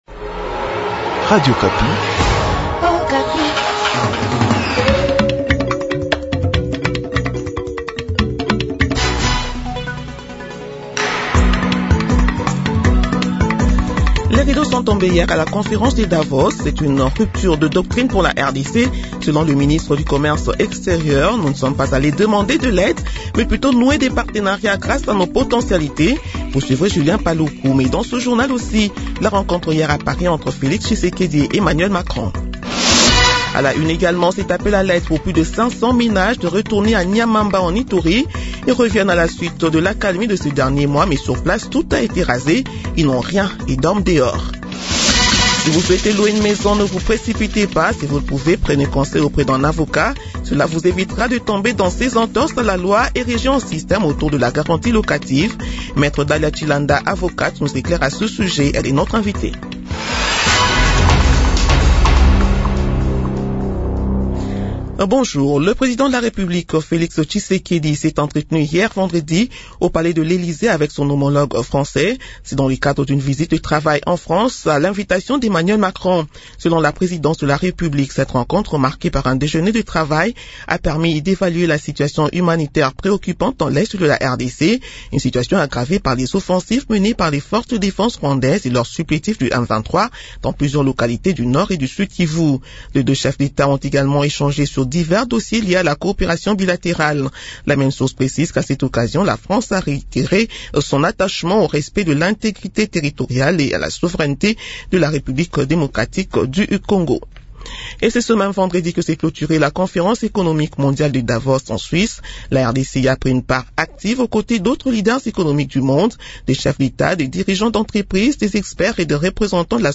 Journal de 8h de samedi 24 janvier